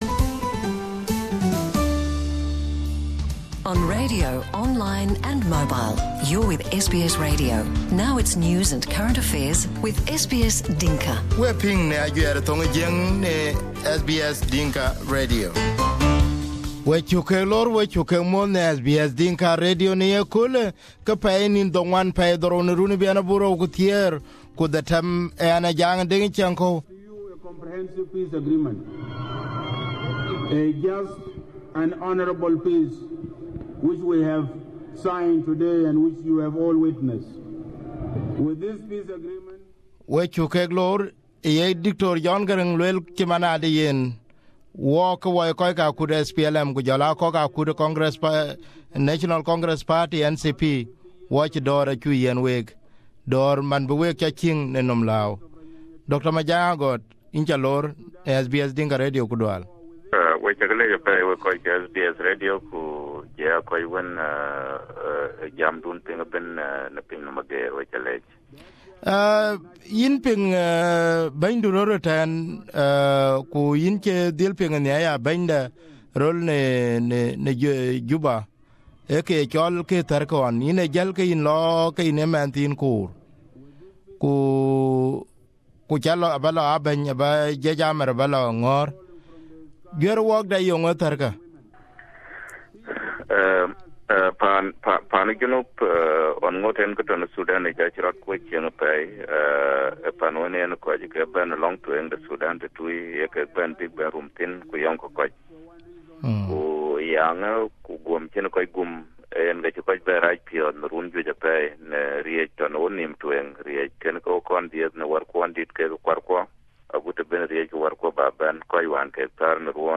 Dr Majak Agot comments on SBS Dinka Radio about the situation in Juba and also the 5th anniversary of Sudan's independence.